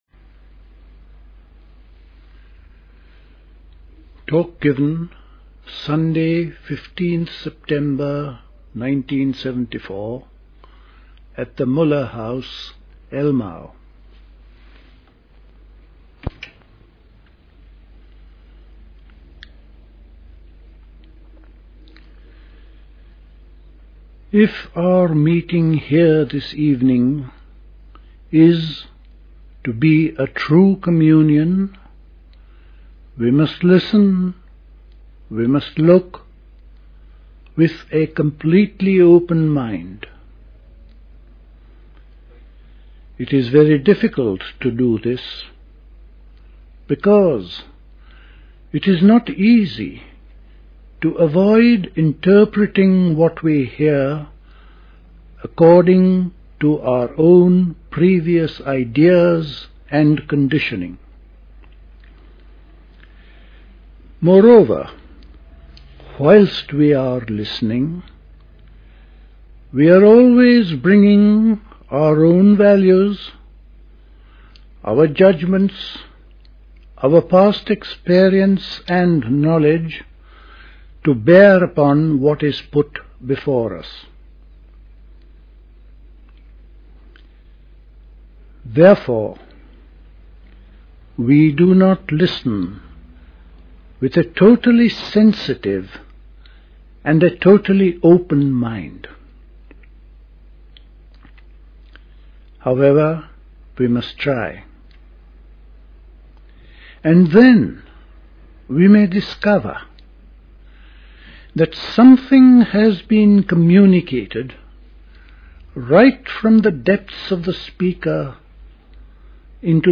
Recorded at the 1974 Elmau Summer School.